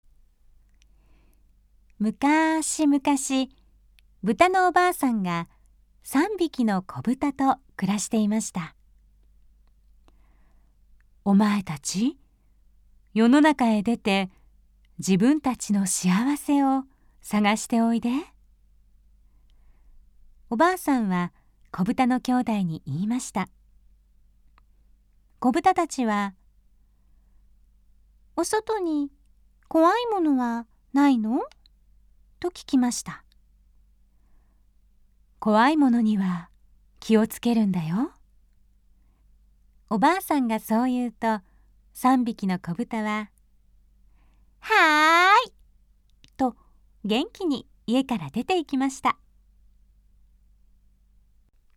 日本語 女性｜ナレーター紹介